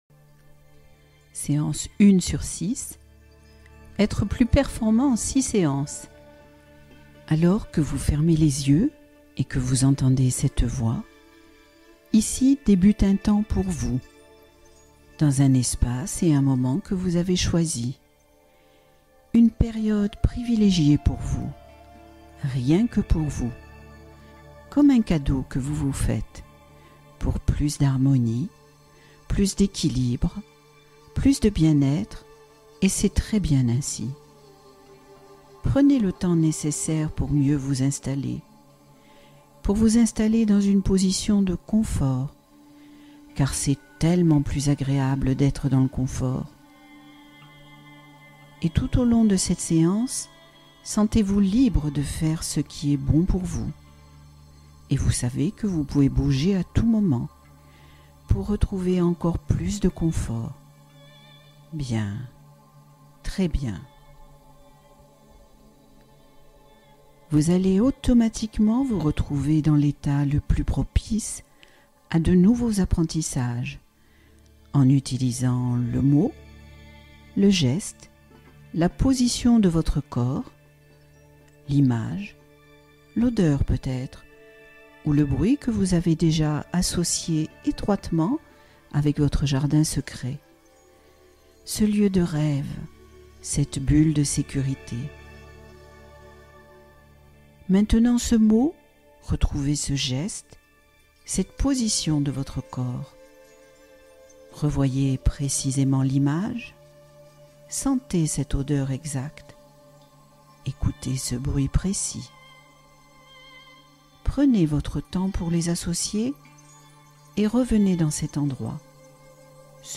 Réactiver la joie de vivre — Hypnose en cheminement progressif